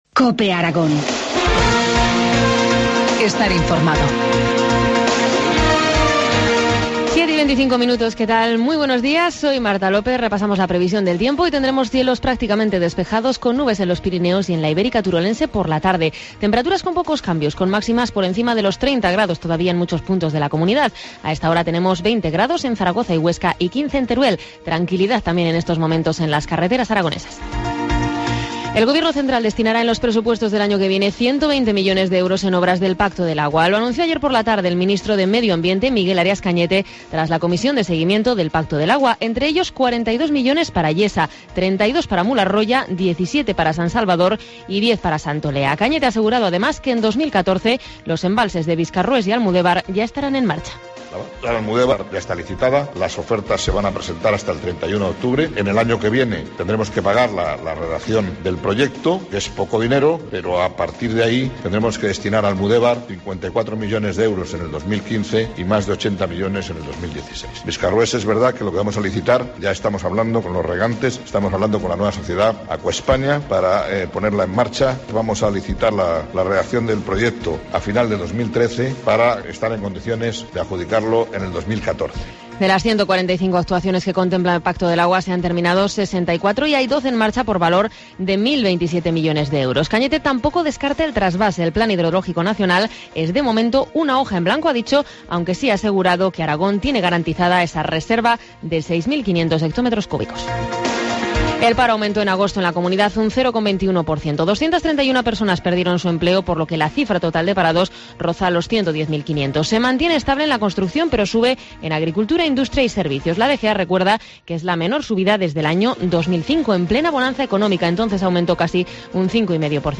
Informativo matinal, miércoles 4 de septiembre, 7.25 horas